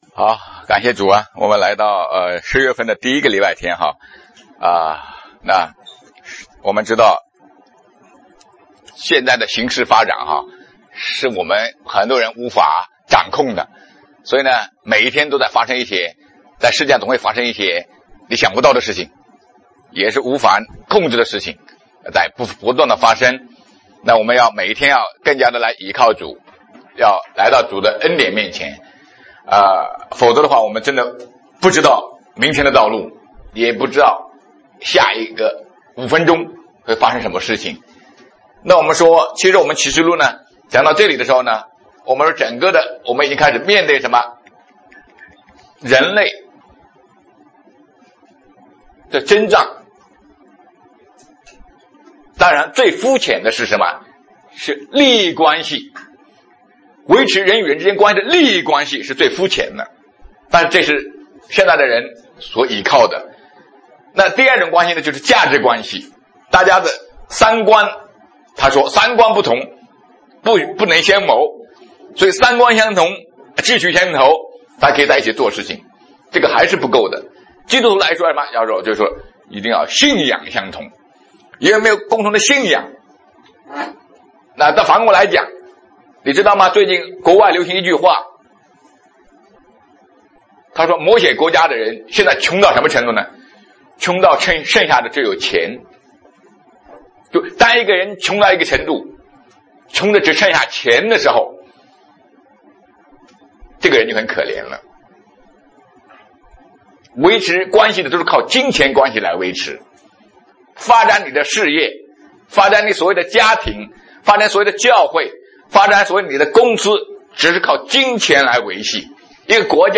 启示录第二十八讲 证道主题：两个筵席（二） 2020年10月4日 上午11:27 作者：admin 分类： 启示录圣经讲道 阅读(4.24K